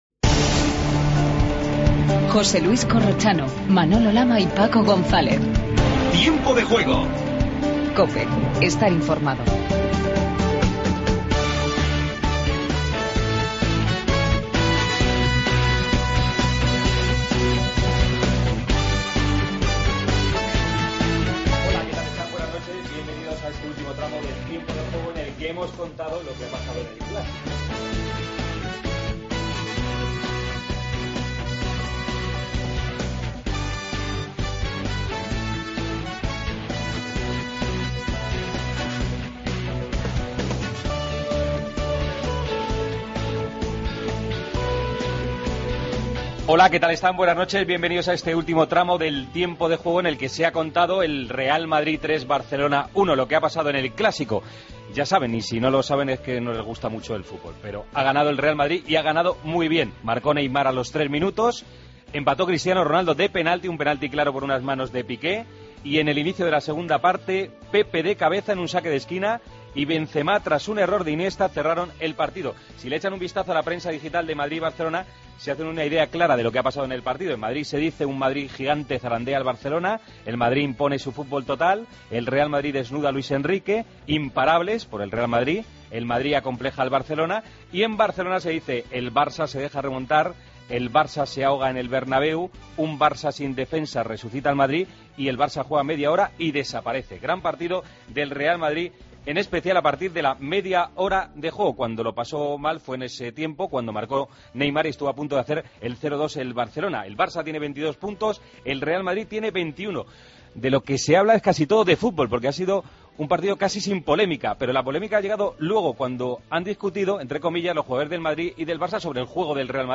Titulares del día. Escuchamos a Xavi y Pepe en zona mixta.